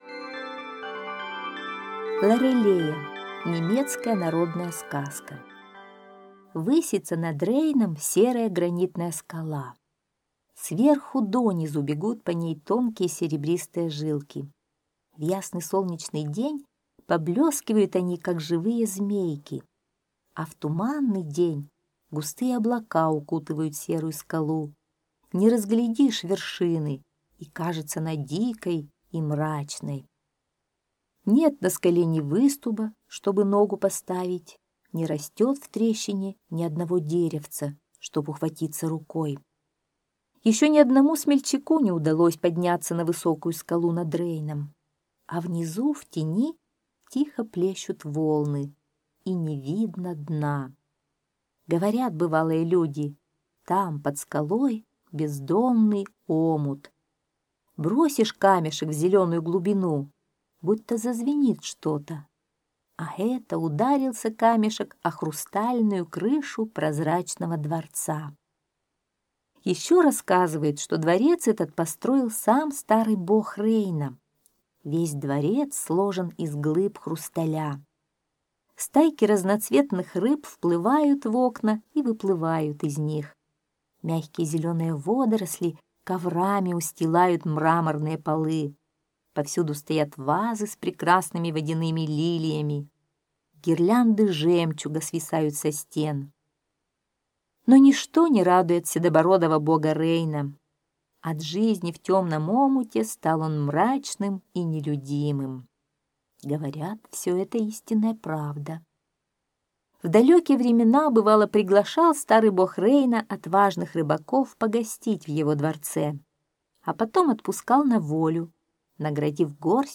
Лорелея - немецкая аудиосказка - слушать онлайн